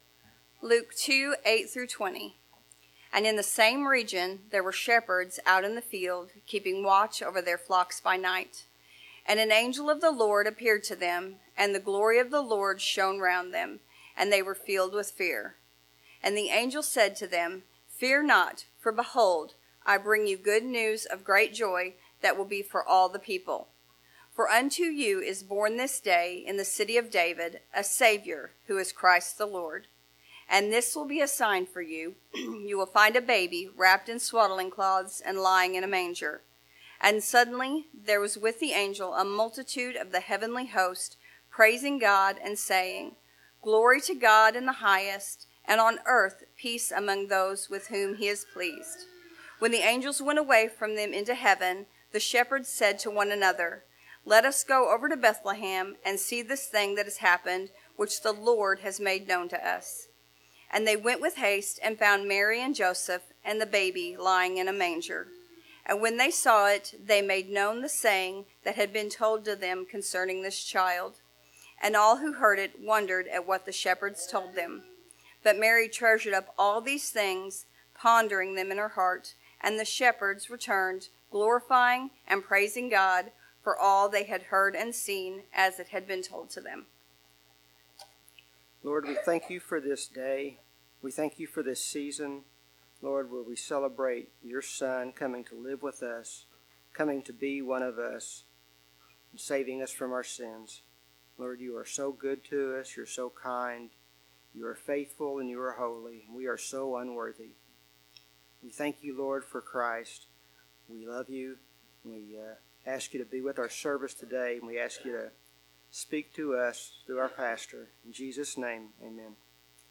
Advent 2024 Passage: Luke 2:8-20 Service Type: Sunday Morning Related Topics